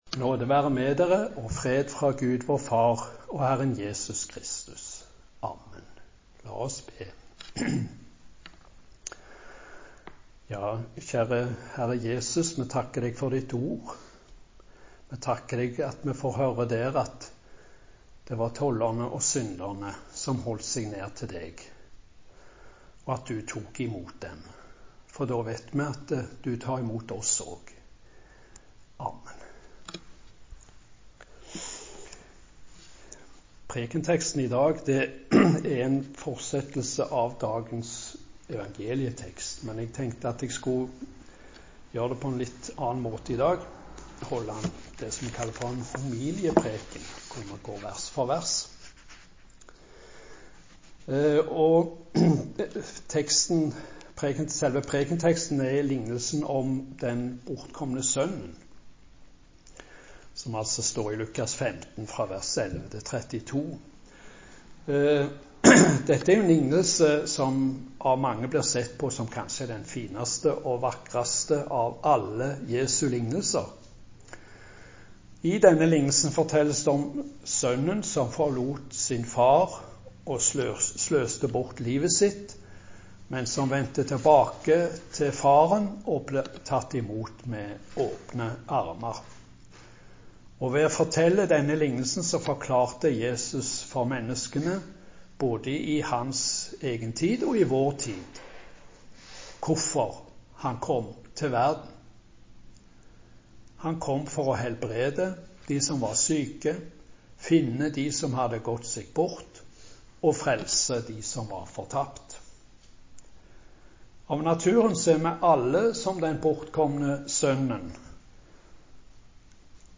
Preken